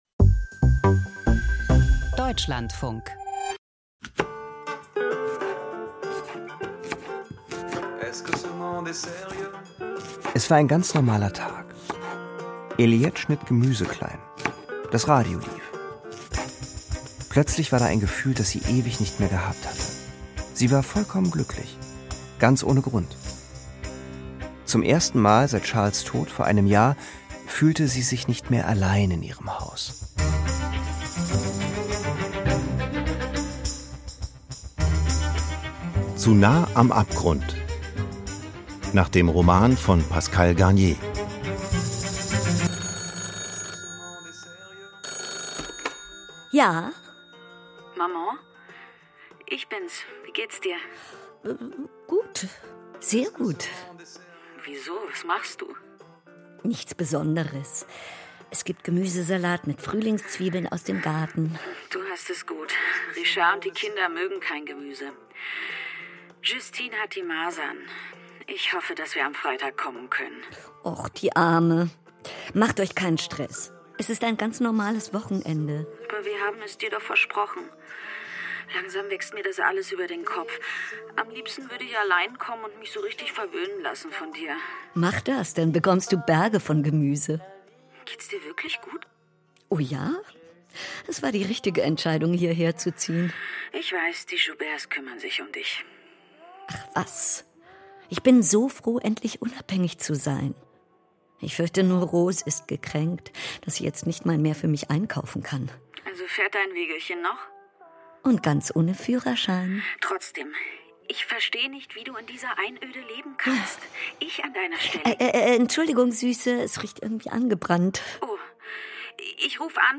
Krimi-Hörspiel: Französischer Roman Noir - Zu nah am Abgrund